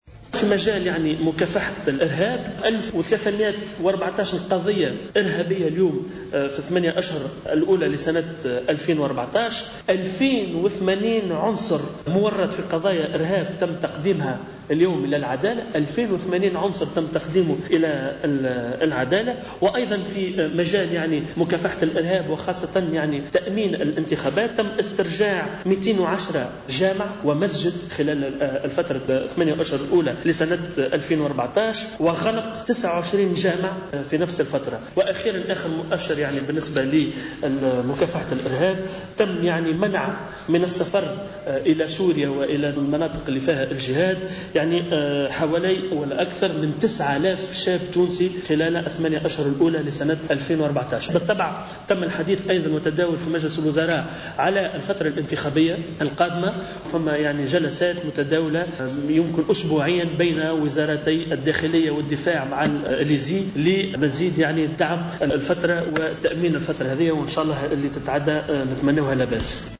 وبالنسبة للوضع الأمني وخاصة في ما يتعلق بالارهاب فصرّح الناطق الرسمي باسم الحكومة نضال الورفلي بأنه تم تسجيل ألف وثلاث مئة وأربع عشرة قضية إرهابية خلال الثماني أشهر الأولى من ألفين وأربعة عشر وقد قدم المتورطون في هذه القضايا اليوم للعدالة. وأضاف الورفلي في تصريح إعلامي عقب هذا المجلس أنه تم استرجاع 210 جامع ومسجد كانت خراج عن سيطرة وزارة الشؤون الدينية خلال ذات الفترة كما تم منع قرابة 9000 شاب من السفر للقتال خراج حدود تونس.